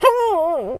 dog_hurt_whimper_howl_03.wav